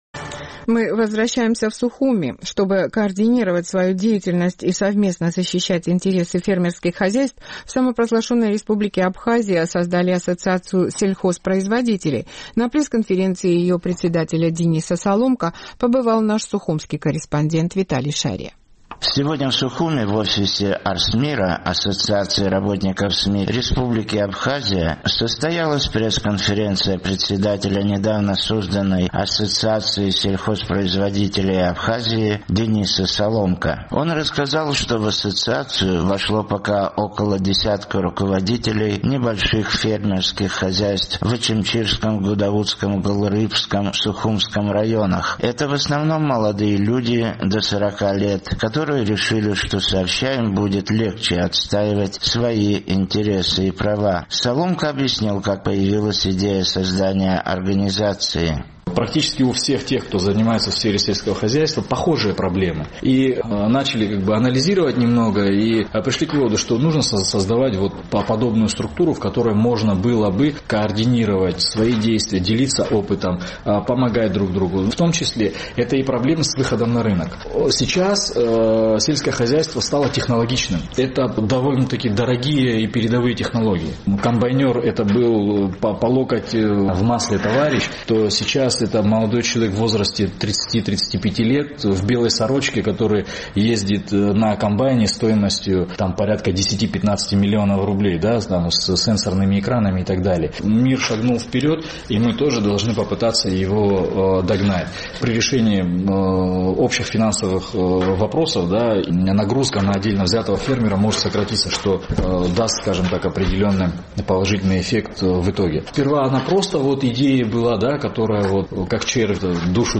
Сегодня в Сухуме, в офисе АРСМИРА (Ассоциации работников СМИ Республики Абхазия) состоялась пресс-конференция